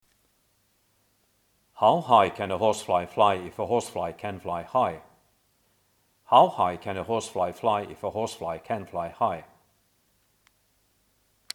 Horse-fly.mp3